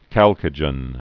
(kălkə-jən)